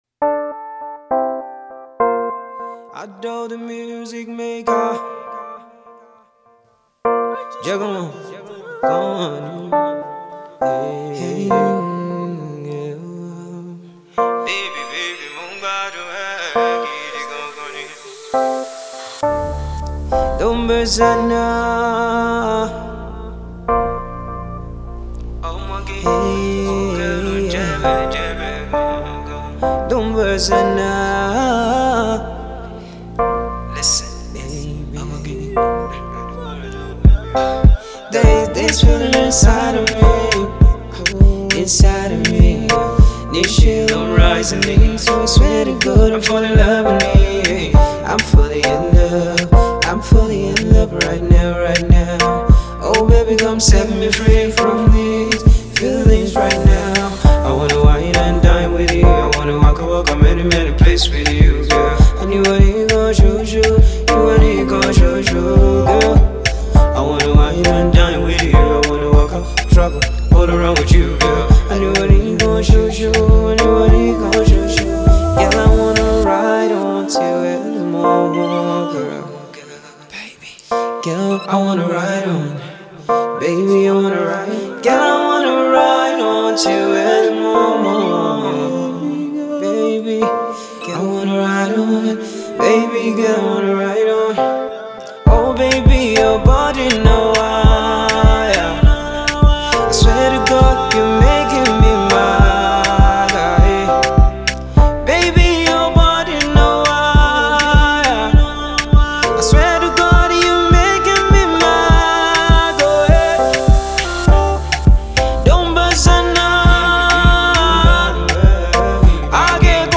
infectious tune/banger